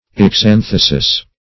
Search Result for " exanthesis" : The Collaborative International Dictionary of English v.0.48: Exanthesis \Ex`an*the"sis\, n. [NL., from Gr.